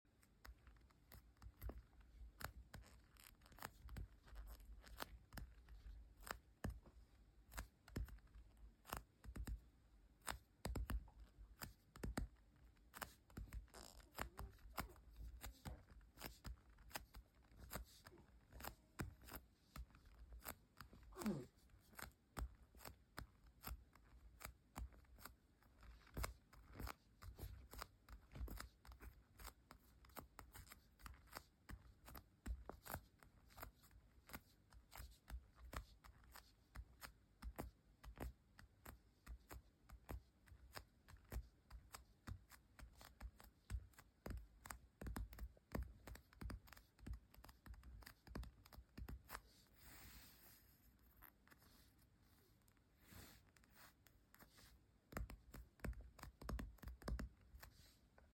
ASMR Sticky Trigger Sound Sound Effects Free Download